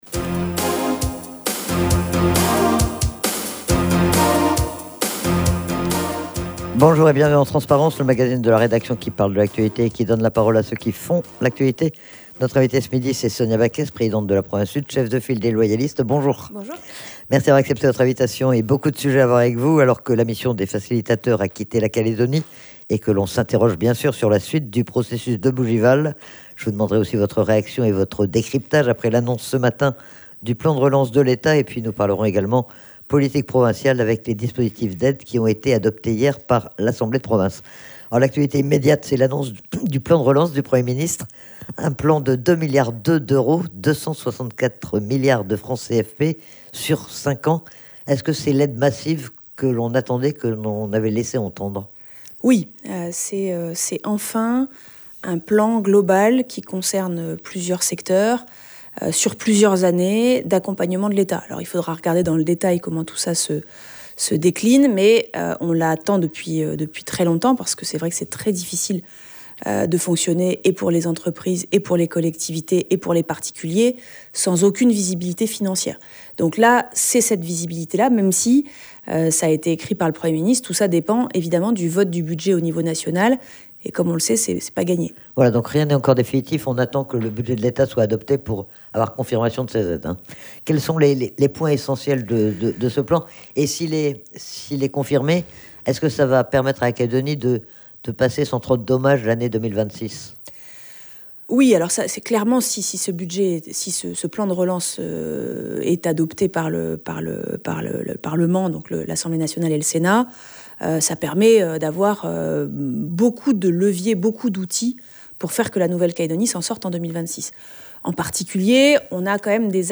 Elle était interrogée sur les discussions autour de l'accord de Bougival et plus globalement sur l'actualité politique calédonienne et sur l'actualité provinciale.